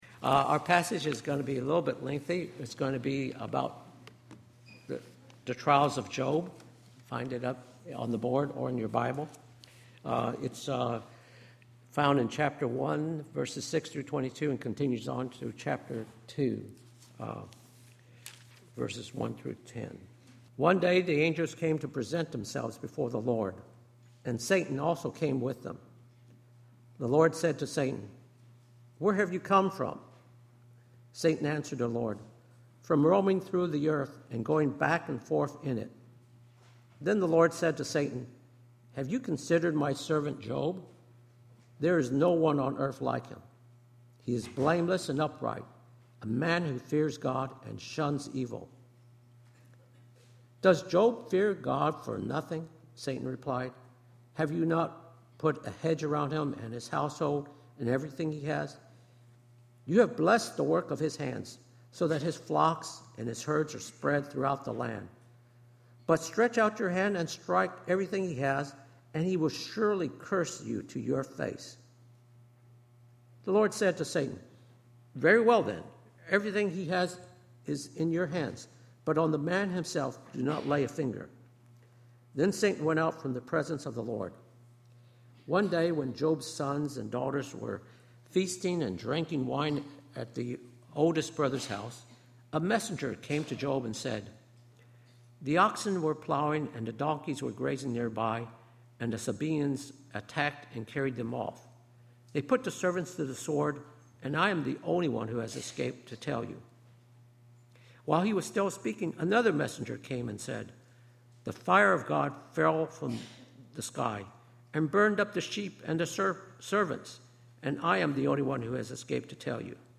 Presentation to accompany sermon